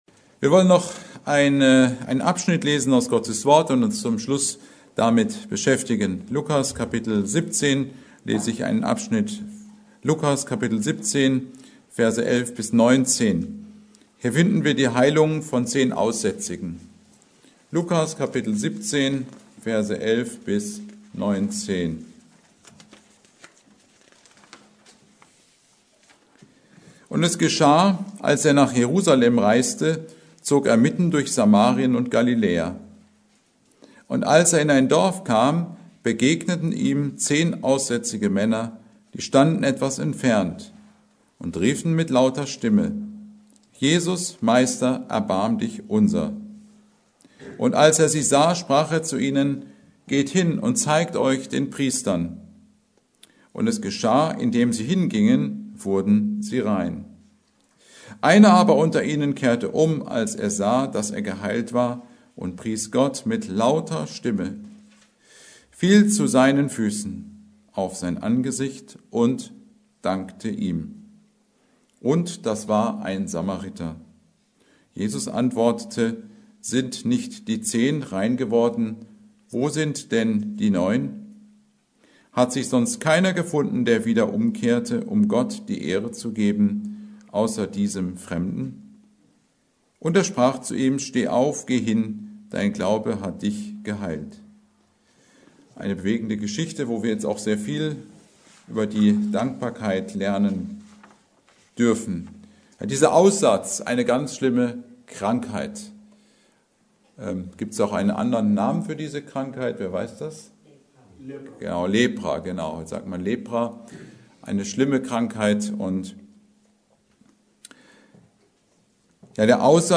Predigt: Heilung von zehn Aussätzigen